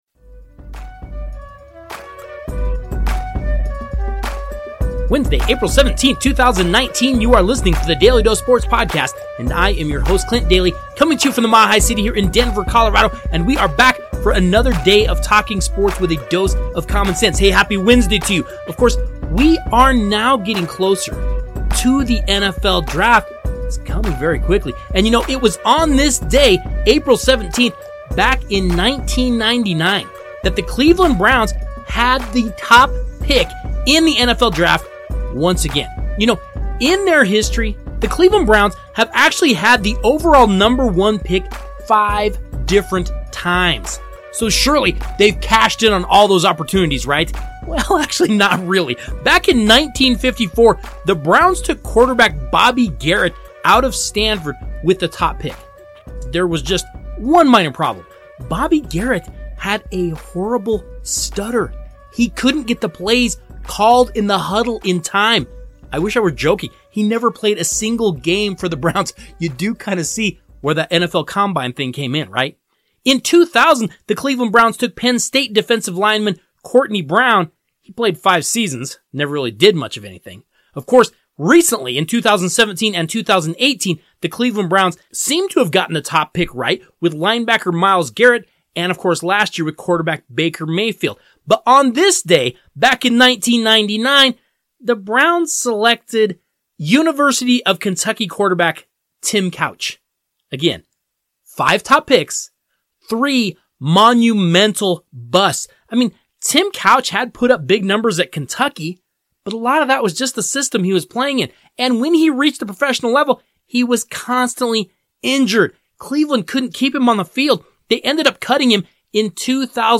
Then, we continue our conversation with longtime basketball coach